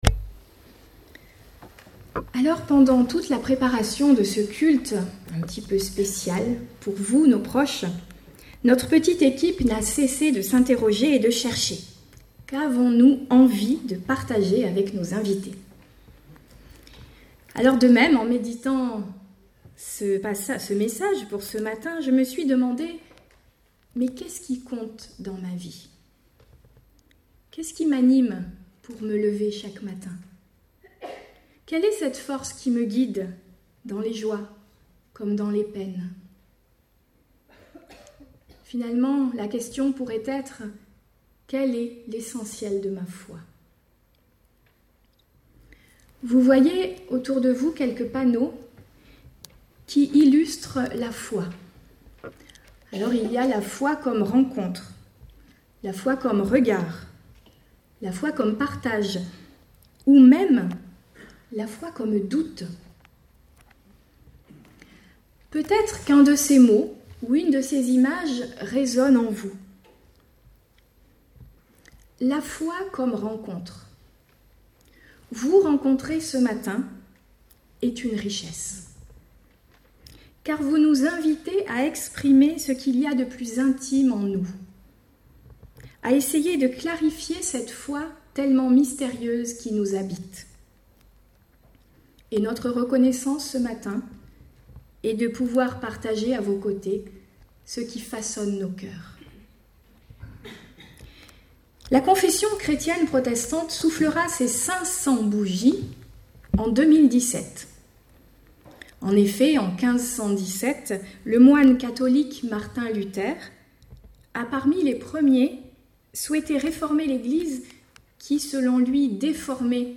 Podcasts prédications